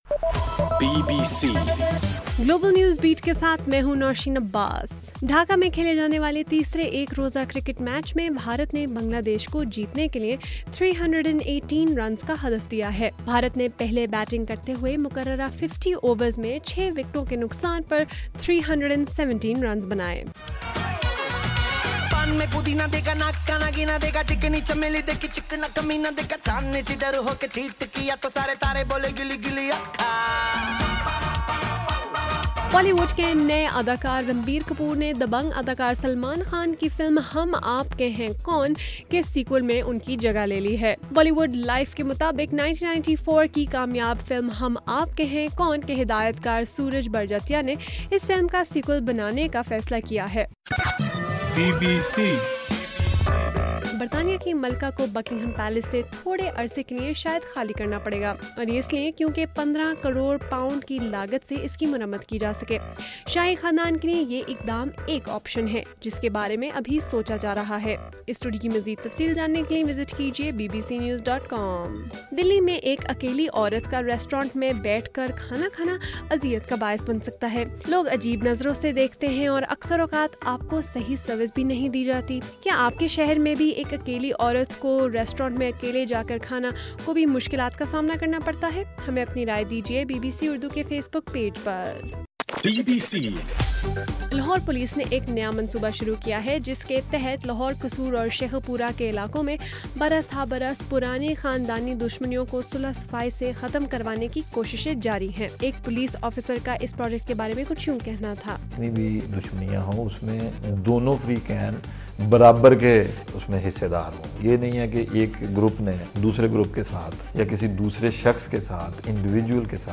جون 24: رات 10بجے کا گلوبل نیوز بیٹ بُلیٹن